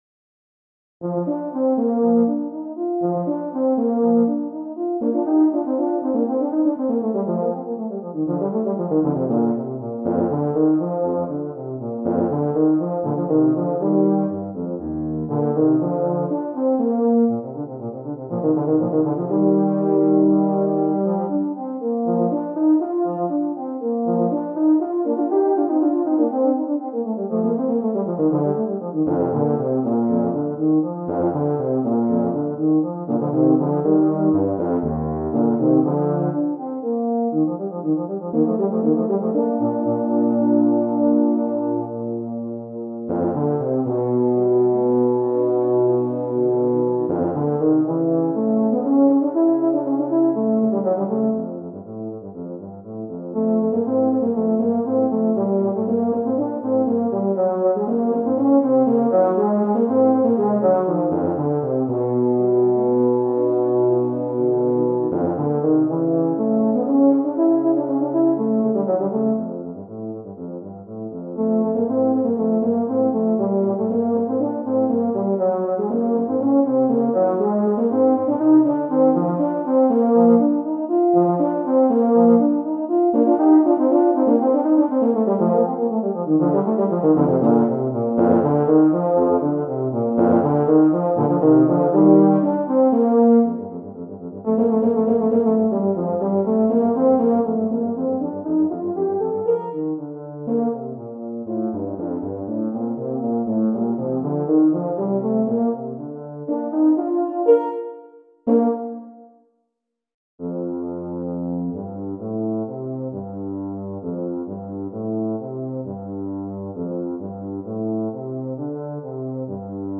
Voicing: Euphonium Duet